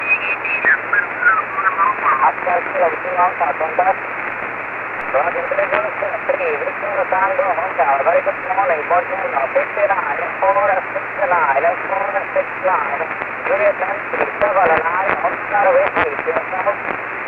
IARU VHF 09/2014 CATEGORIA 6 ORE